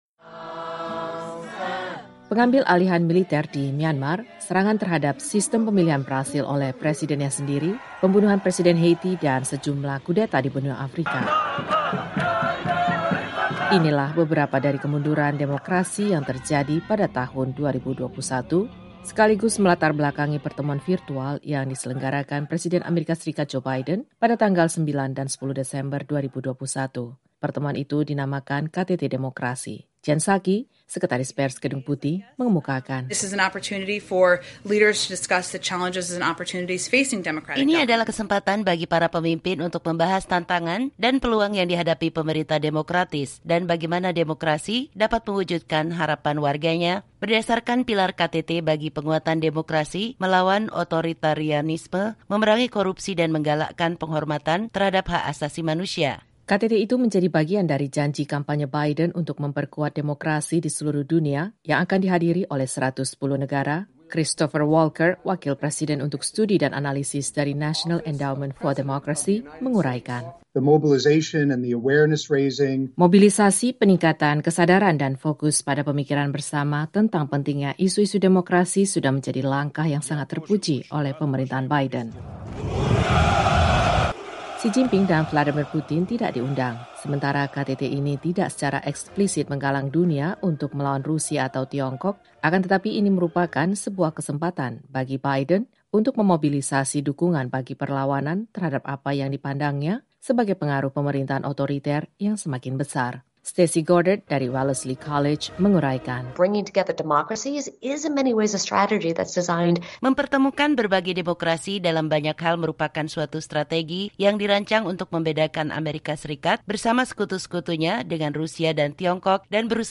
Presiden Joe Biden menjadi tuan rumah KTT virtual untuk Demokrasi pada 9 dan 10 Desember yang mempertemukan para pemimpin dunia, masyarakat sipil dan sektor swasta untuk melawan otoritarianisme, memerangi korupsi dan menggalakkan penegakan hak asasi manusia. Laporan VOA berikut